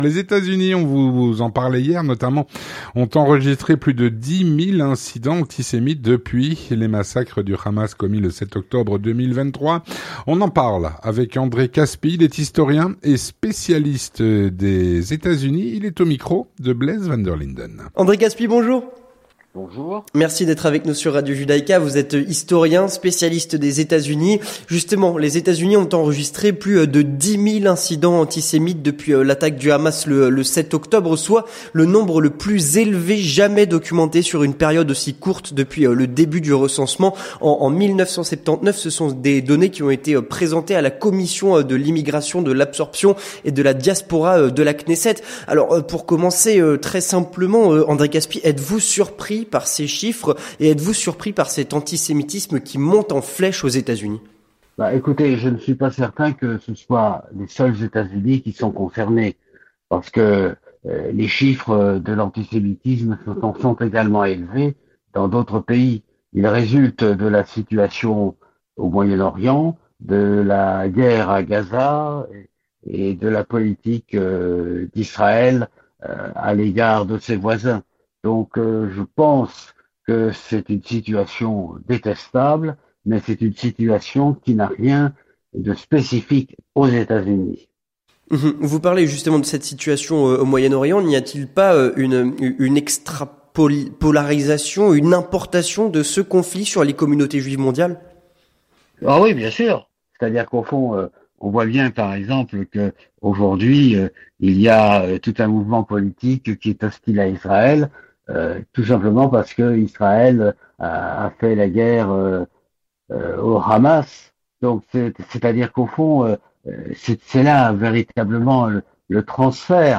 L'entretien du 18H - Les États-Unis ont enregistré plus de 10 000 incidents antisémites depuis l'attaque du Hamas le 7 octobre. Avec André Kaspi (09/01/2025)
On en parle avec André Kaspi, historien et spécialiste des USA.